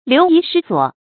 流移失所 注音： ㄌㄧㄨˊ ㄧˊ ㄕㄧ ㄙㄨㄛˇ 讀音讀法： 意思解釋： 猶言流離失所。